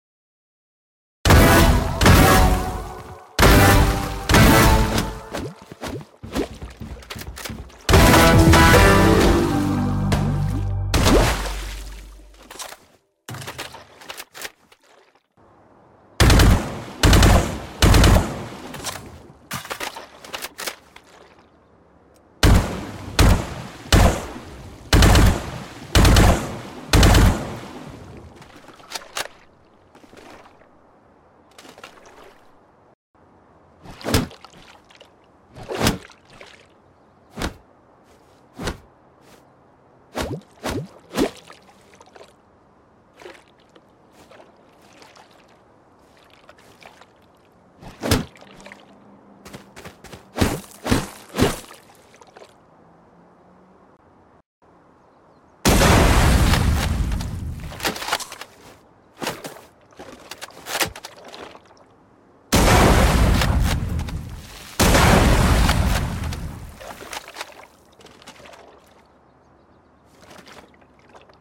NEW SplashX Vandal, Melee & sound effects free download